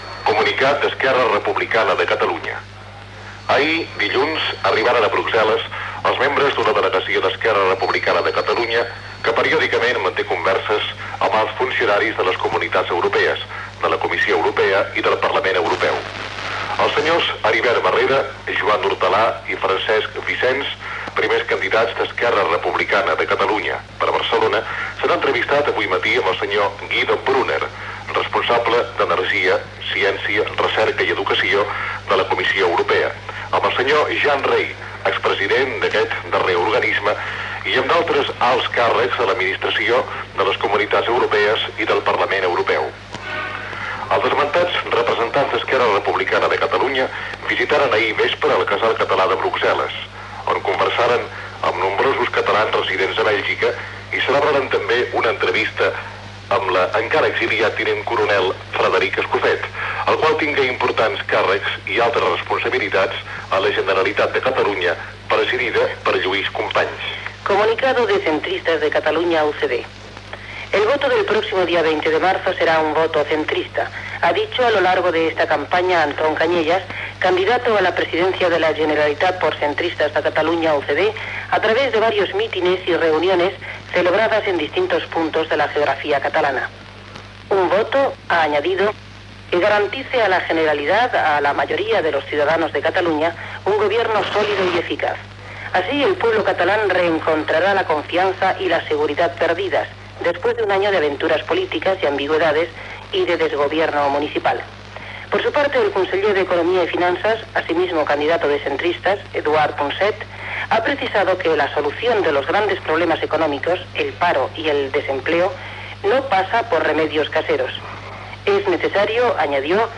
Comunicats d'Esquerra Republicana (anada de polítics del partit a la Comissió Europea de Brusel·les) i Centristes de Catalunya-Unión de Centro Democrático (opinions dels candidats Anton Cañellas i Eduard Punset). Sintonia de RNE, publcitat local diversa i tema musical
Informatiu